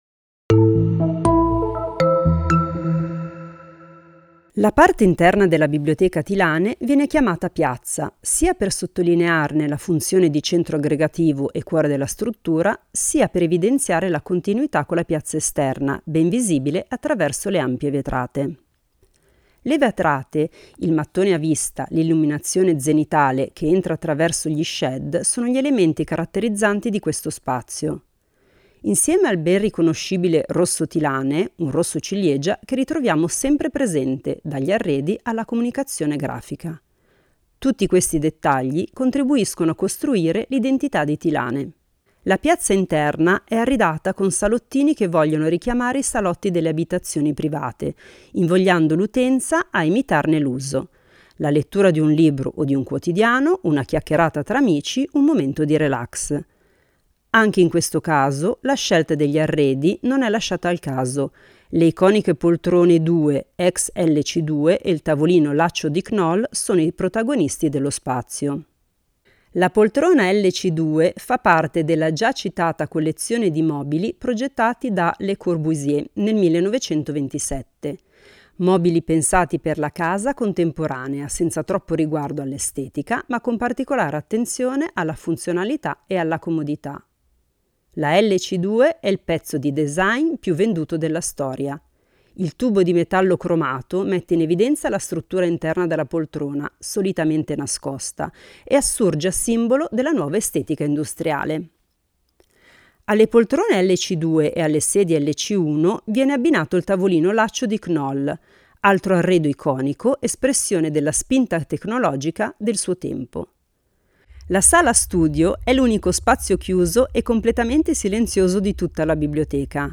Visita guidata ad alta voce